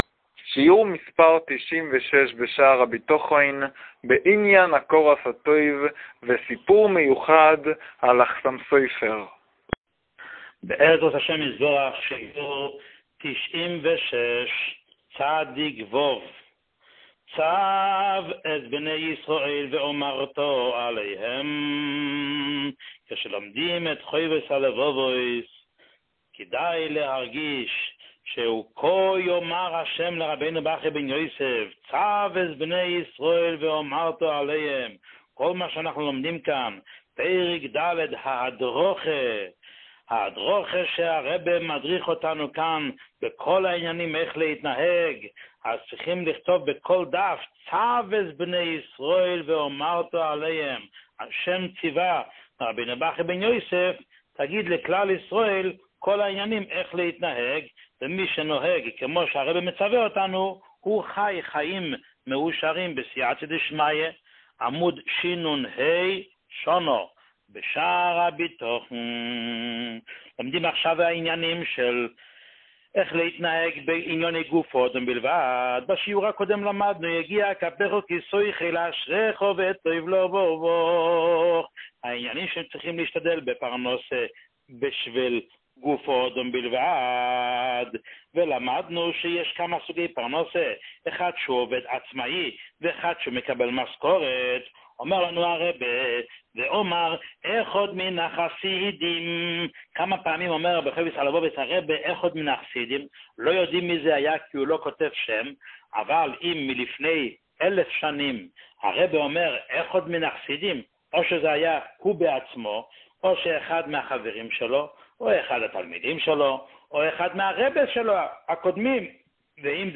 שיעור 96